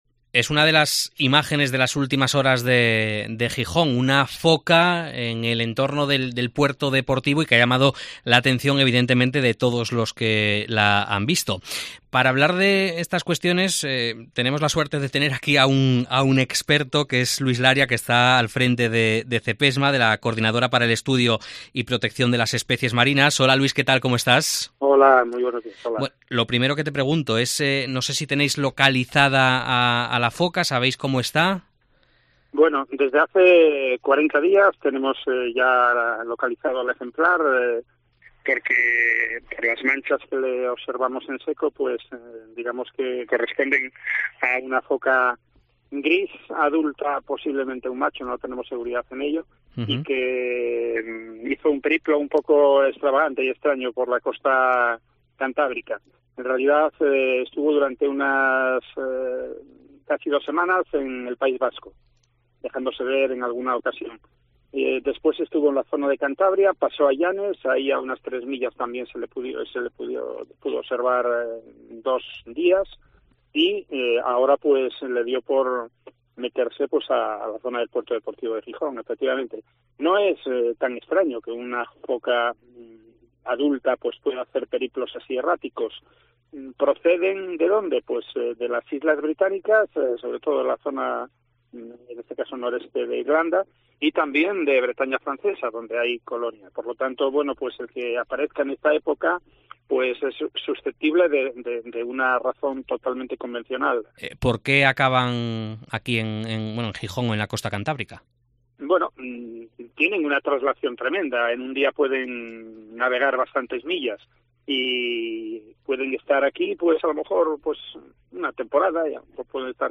Aparece una foca en Gijón: entrevista